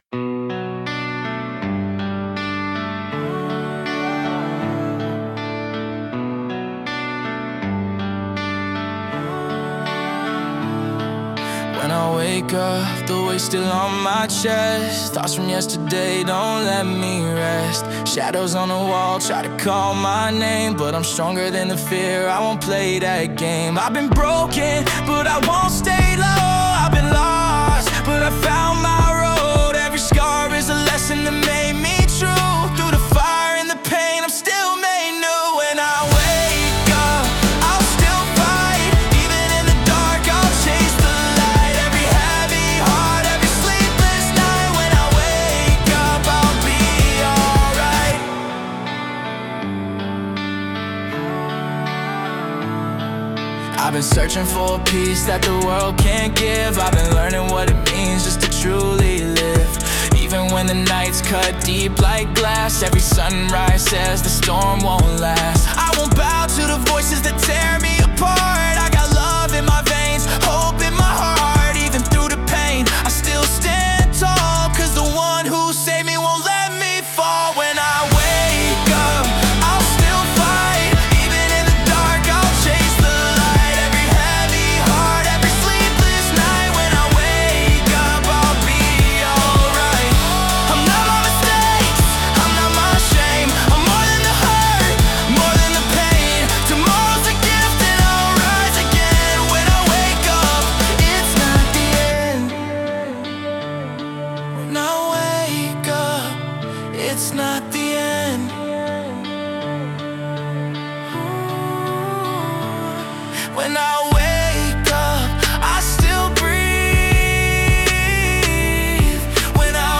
Royalty-Free Christianity music track
Genre: Christianity